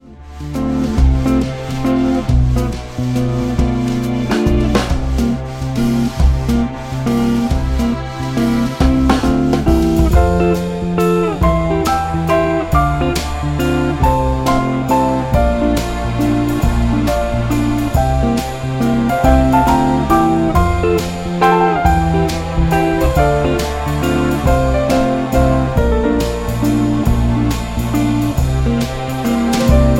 MPEG 1 Layer 3 (Stereo)
Backing track Karaoke
Pop, 2000s